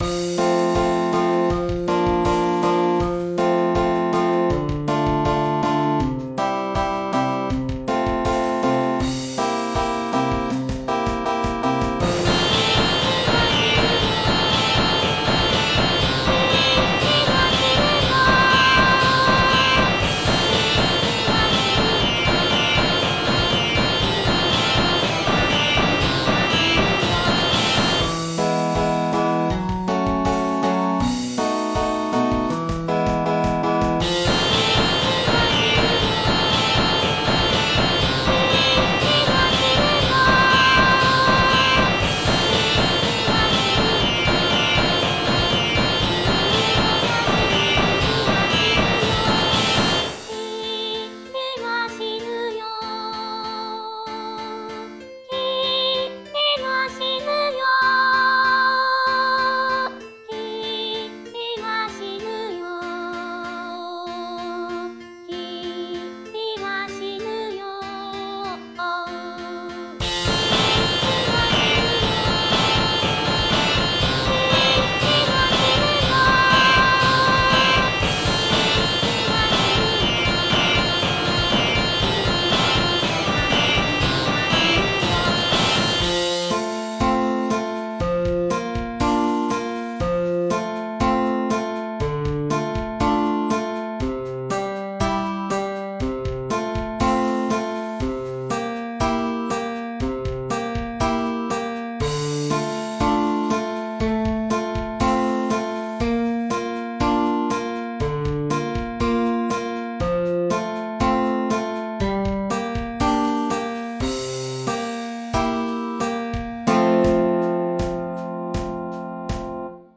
MPEG ADTS, layer III, v2, 128 kbps, 16 kHz, Monaural